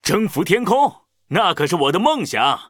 文件 文件历史 文件用途 全域文件用途 Kagon_amb_03.ogg （Ogg Vorbis声音文件，长度2.7秒，104 kbps，文件大小：34 KB） 源地址:地下城与勇士游戏语音 文件历史 点击某个日期/时间查看对应时刻的文件。 日期/时间 缩略图 大小 用户 备注 当前 2018年5月13日 (日) 02:14 2.7秒 （34 KB） 地下城与勇士  （ 留言 | 贡献 ） 分类:卡坤 分类:地下城与勇士 源地址:地下城与勇士游戏语音 您不可以覆盖此文件。